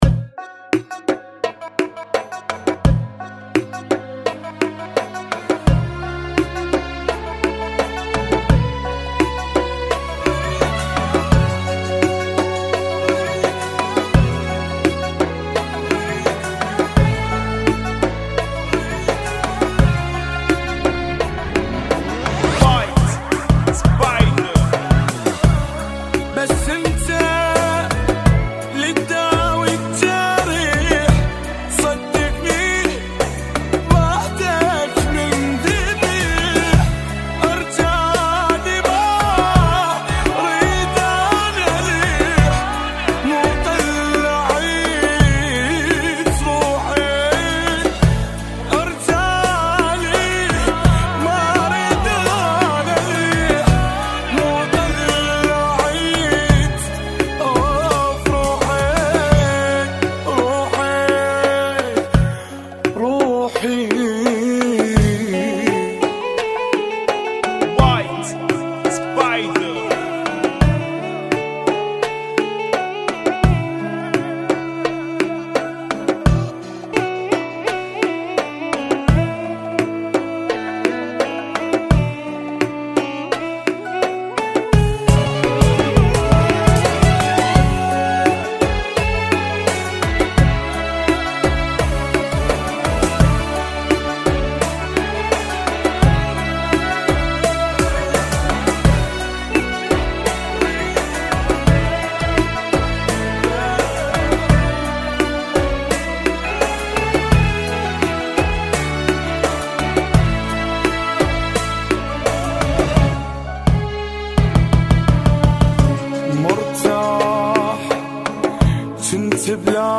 Funky [ 85 Bpm ]